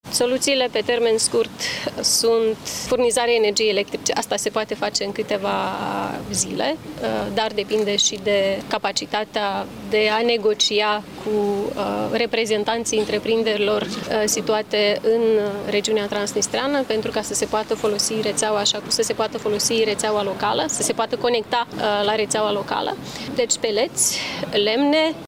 Maia Sandu, președina Republicii Moldova: Furnizarea energiei electrice se poate face în câteva zile.